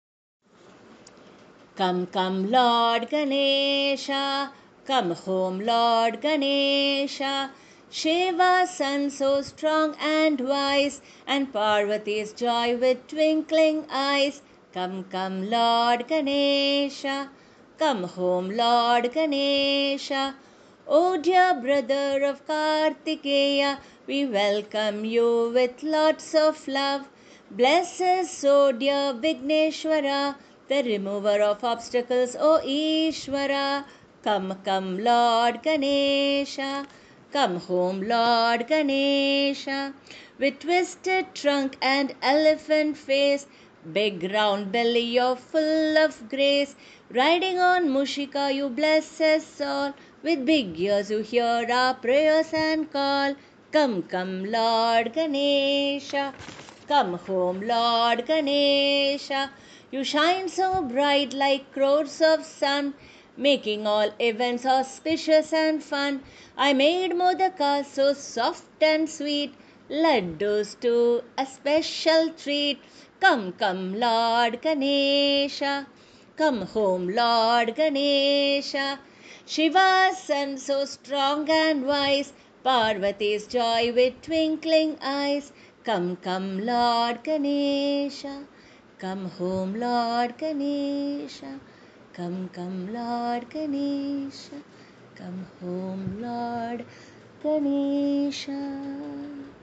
Come Come Lord Ganesha (Action song for young children)